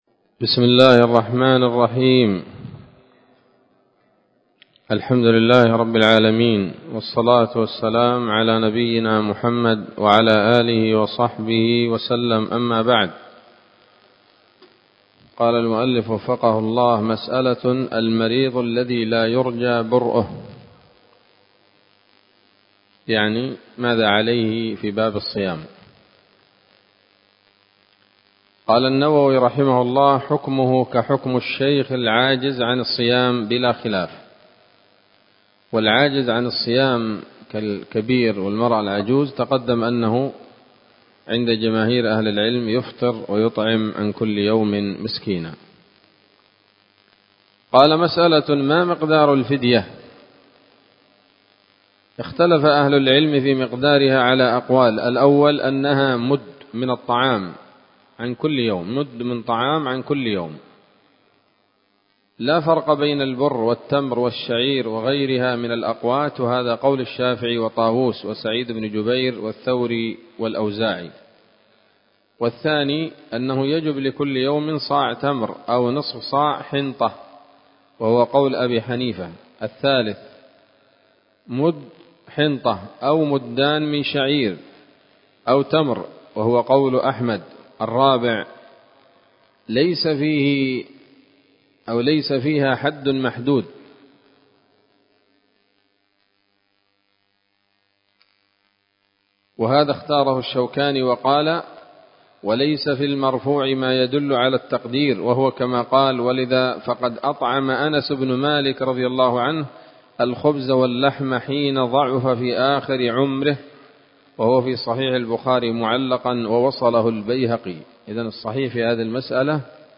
الدرس الثامن عشر من كتاب الصيام من نثر الأزهار في ترتيب وتهذيب واختصار نيل الأوطار